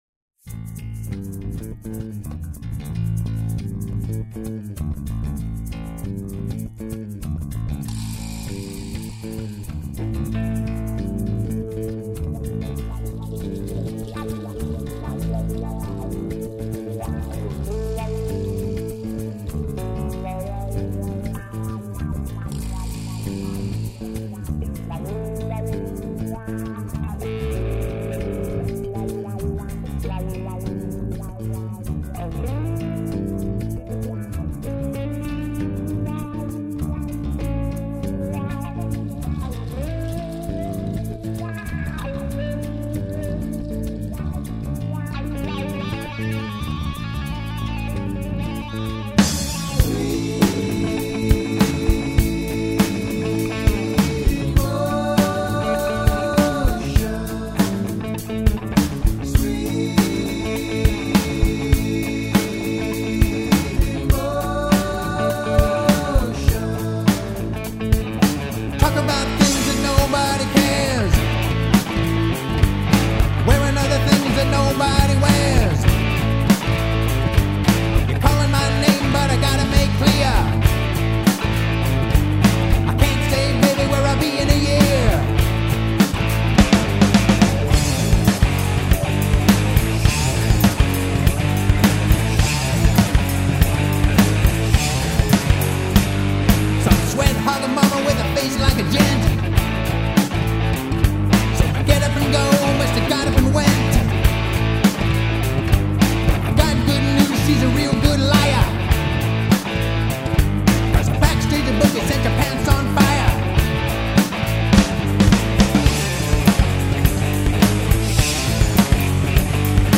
Live Music!